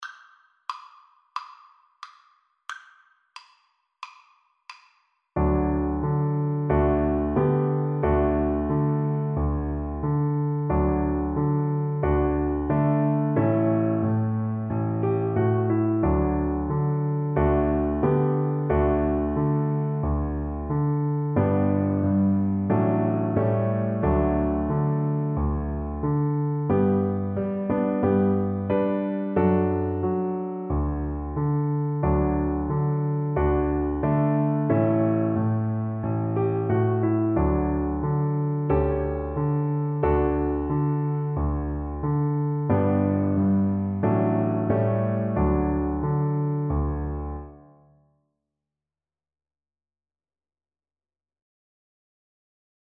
Cello
4/4 (View more 4/4 Music)
D major (Sounding Pitch) (View more D major Music for Cello )
Andante =c.90
Traditional (View more Traditional Cello Music)
Lullabies for Cello